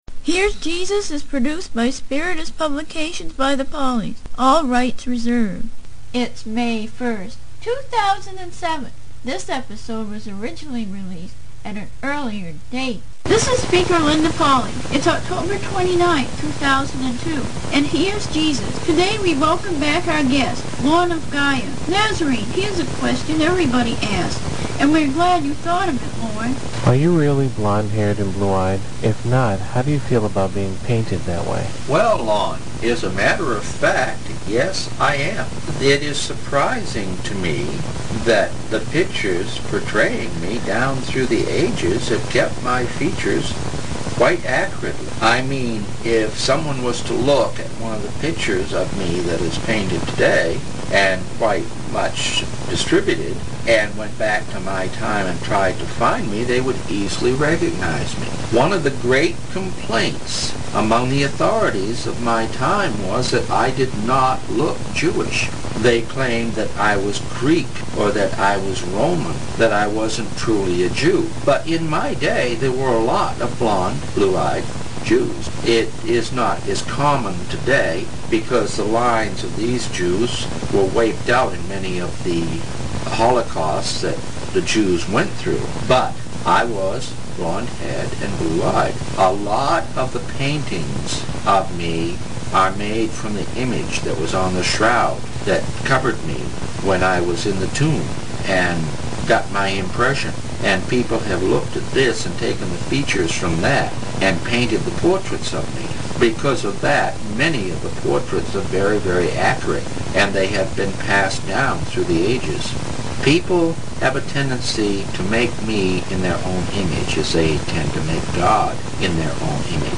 Interviews With
Channeled Through Internationally Known Psychic